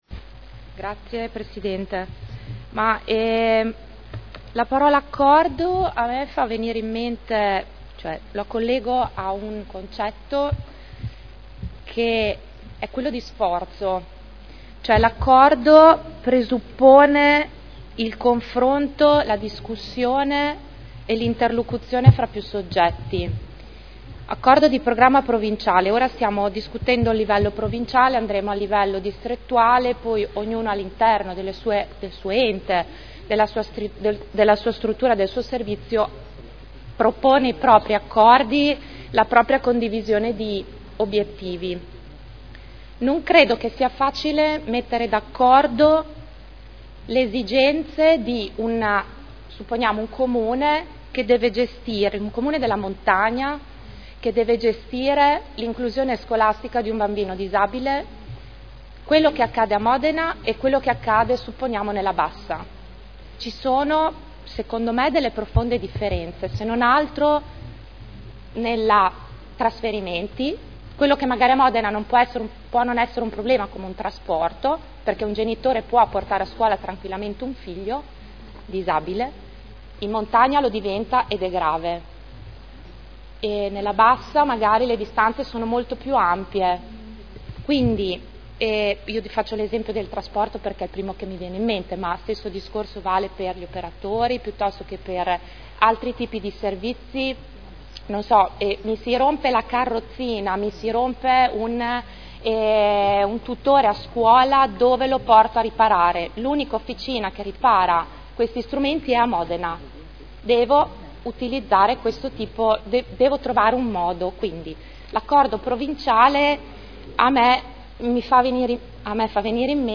Seduta del 07/05/2012. Dibattito.